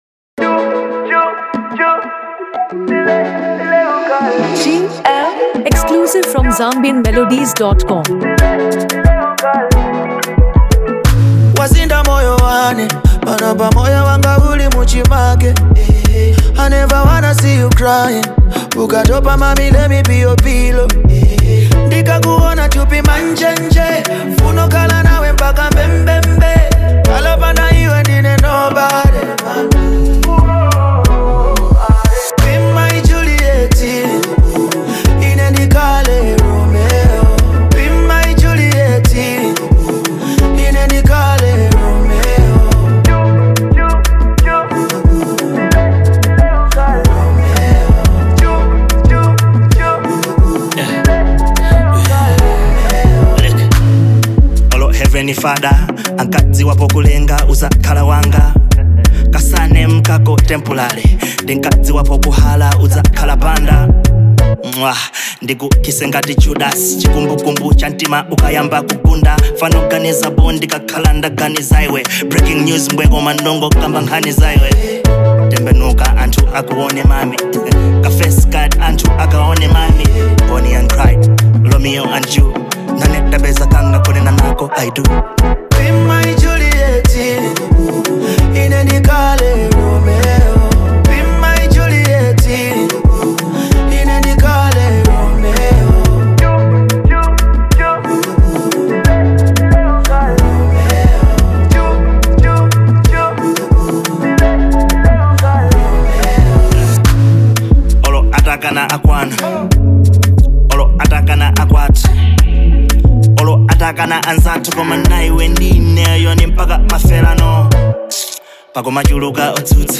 A Unique Blend of Malawian Afro Vibes
This Afro-infused track
delivering a catchy yet deep sound that resonates with fans.
carries a vibrant rhythm layered with meaningful lyrics